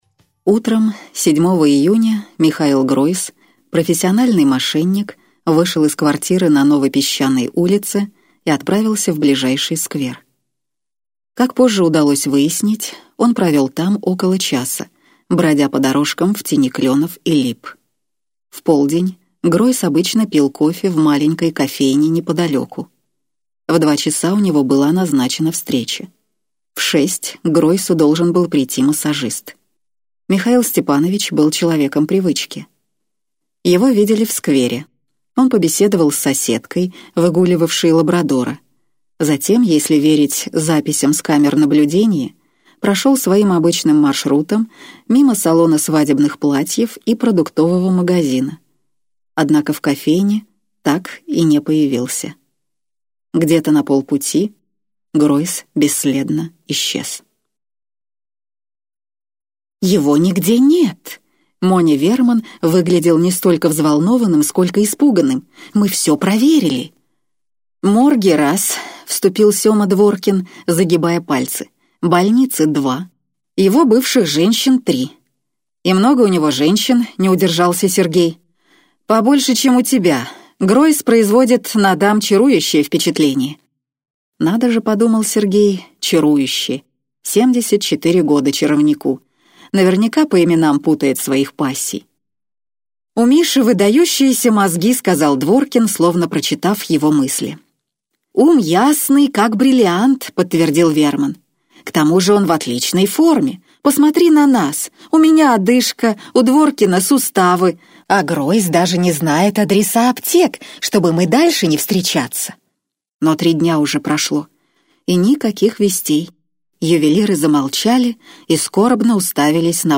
Аудиокнига Закрой дверь за совой - купить, скачать и слушать онлайн | КнигоПоиск